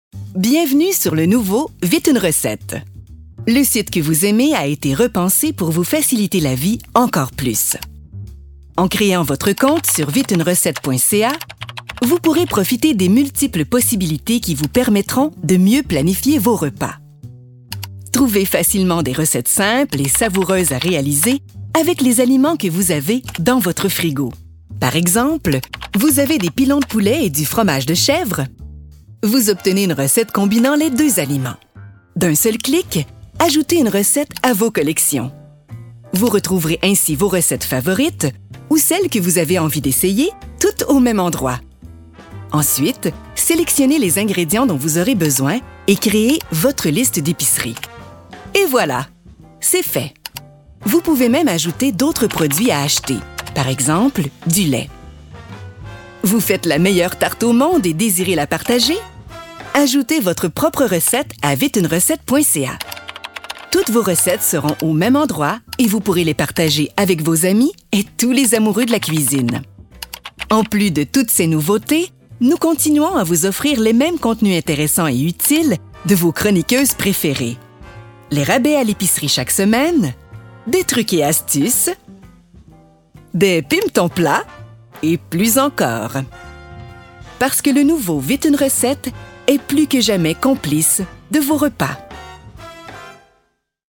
Vídeos explicativos
Locutora francocanadiense profesional, nativa de Quebec, residente en Montreal.
-acento natural de Quebec